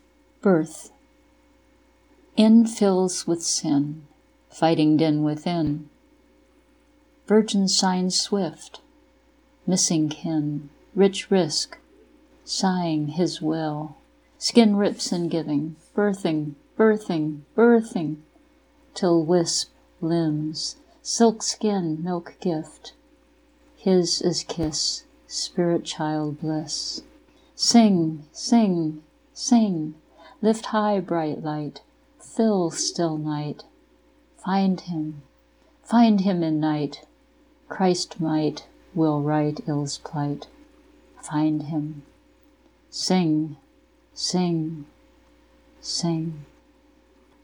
This poem is a lipogram – a poem in which all words contain only one vowel – which is part of what gives it its unusual syntax and rhythm. Thus it is not a smooth and delicate poem – but birth is not a smooth and delicate event.